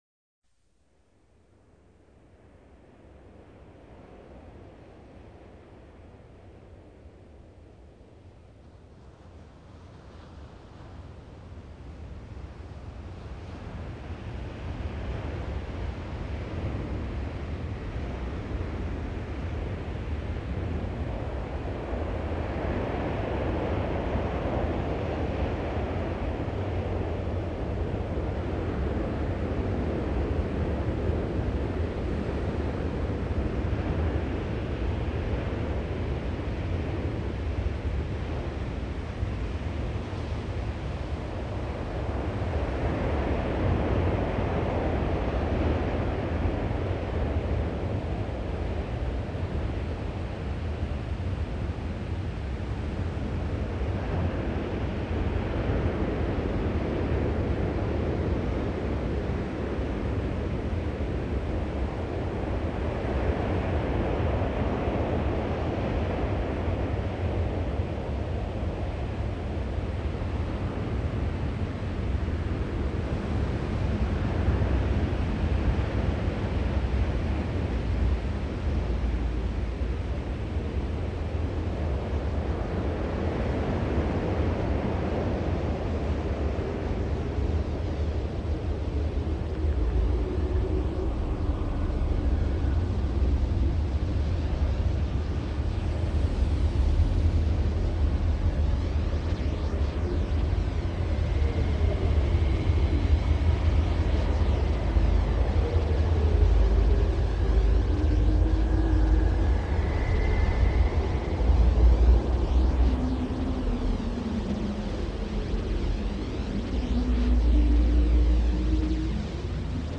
Enspannungs- und Viatalisierungs-Musik
Delfin-Musik